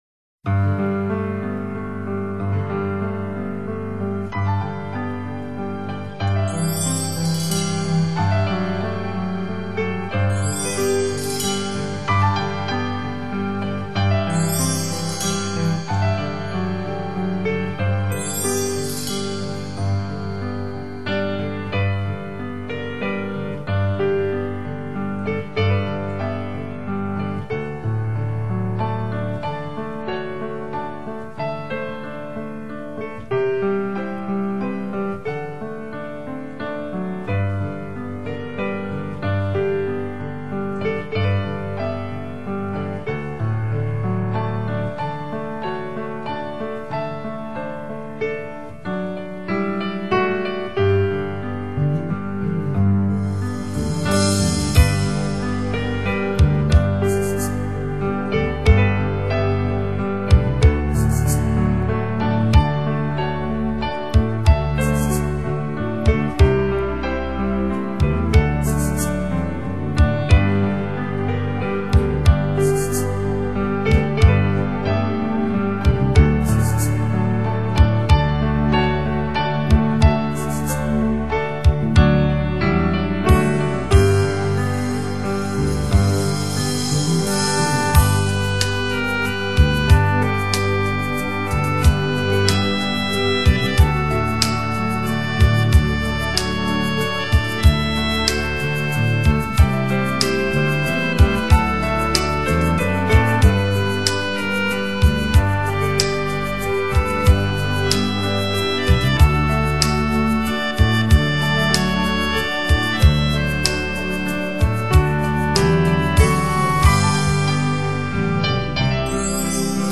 钢琴音乐